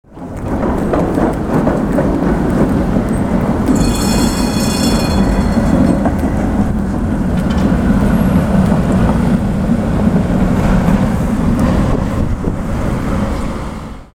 Tram Moving Through Urban Street Sound Effect
Hear a city tram passing through the street with its bell ringing. The realistic sound captures wheels on tracks, urban ambience, and the tram’s iconic bell.
Tram-moving-through-urban-street-sound-effect.mp3